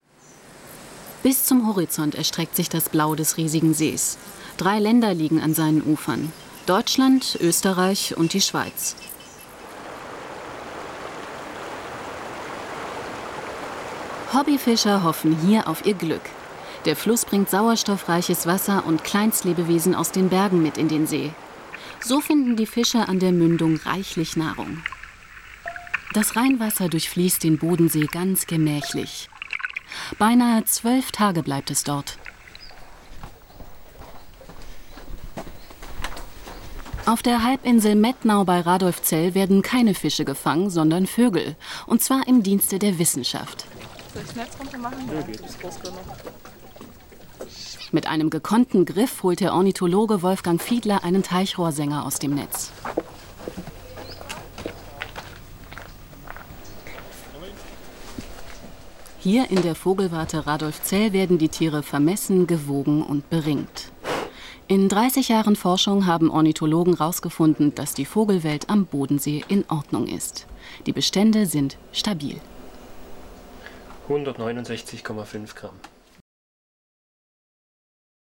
Sprechprobe: eLearning (Muttersprache):
female voice over talent german for commercials, tv, radio, synchron, dubbing, audio-books, documentaries, e-learning, podcast